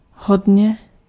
Ääntäminen
RP : IPA : /ˈplɛn.ti/ GenAm: IPA : /ˈplɛn.ti/